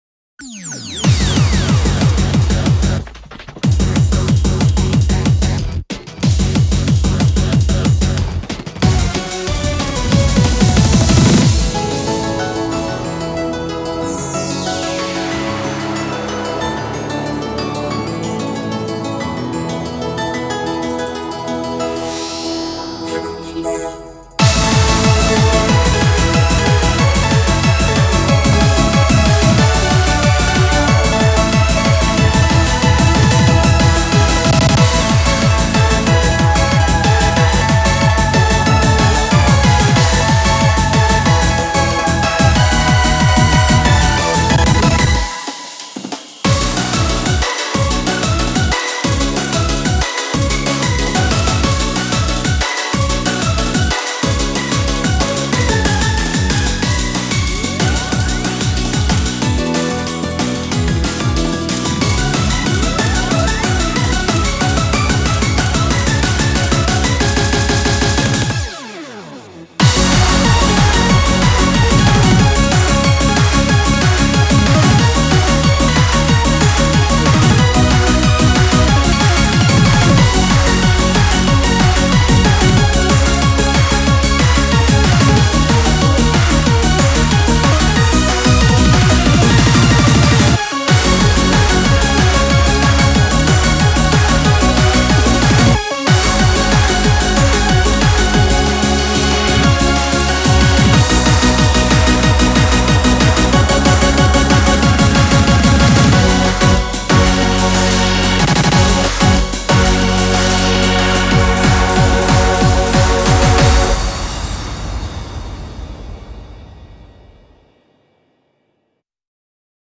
BPM185
Audio QualityPerfect (Low Quality)